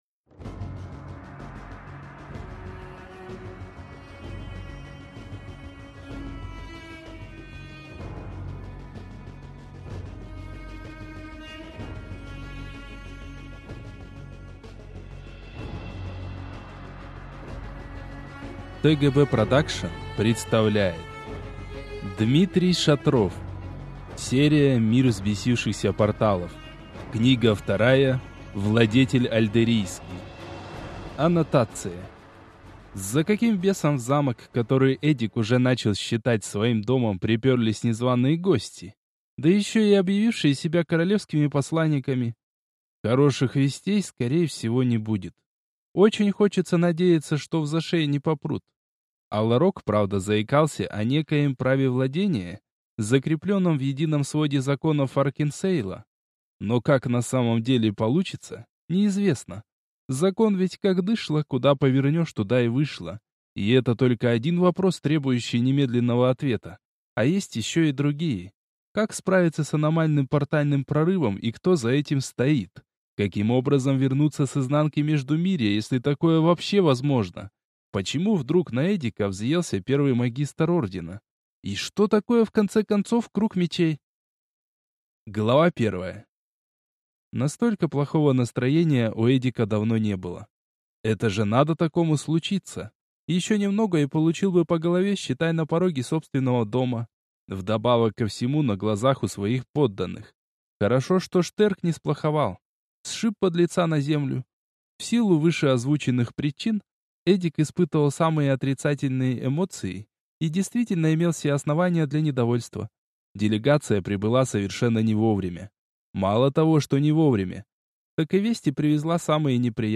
Аудиокнига Владетель Альдеррийский | Библиотека аудиокниг
Прослушать и бесплатно скачать фрагмент аудиокниги